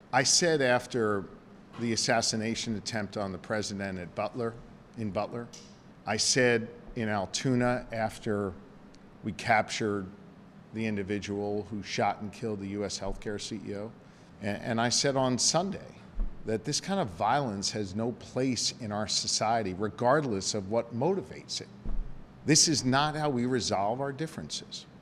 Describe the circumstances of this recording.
At an event in Hershey on Wednesday, the Governor said that his focus has been on being a good dad, husband and Governor, and has talked with his children about what happened.